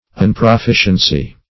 Search Result for " unproficiency" : The Collaborative International Dictionary of English v.0.48: Unproficiency \Un`pro*fi"cien*cy\, n. Want of proficiency or improvement.